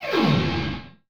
Plasm_gun65.wav